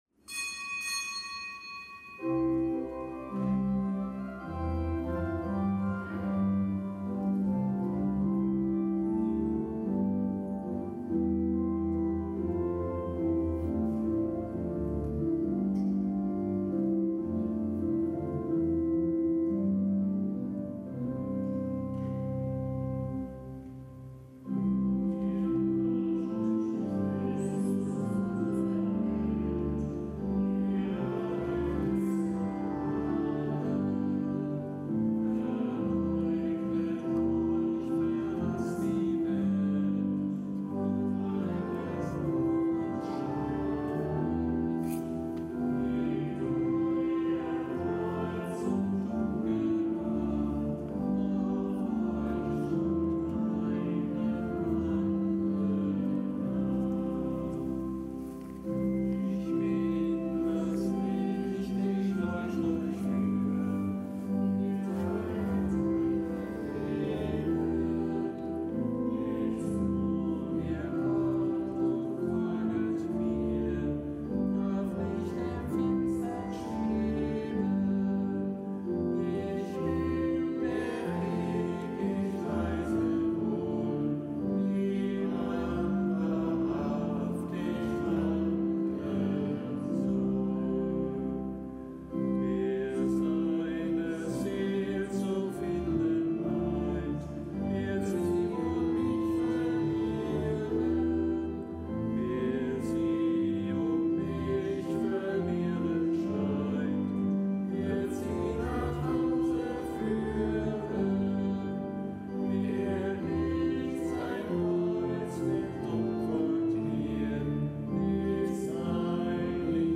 Kapitelsmesse aus dem Kölner Dom am Gedenktag des Heiligen Ignatius von Antiochien, Bischof von Antiochien, Märtyrer. Zelebrant: Weihbischof Dominikus Schwaderlapp.